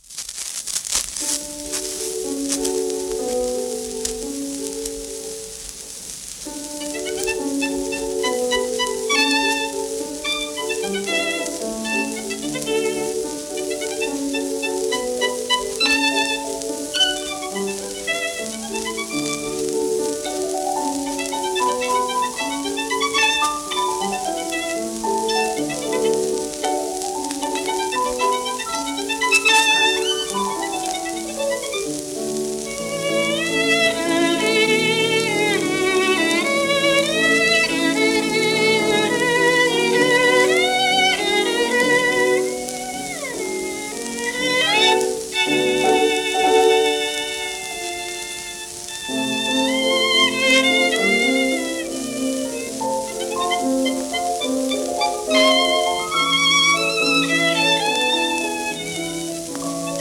盤質A-/B+ *導入部にややノイズ
1931年録音